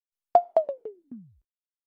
Звук беспроводных наушников Apple AirPods Pro 2 и других в mp3 для монтажа
3. AirPods разряжен, села батарея
airpods-razriajen.mp3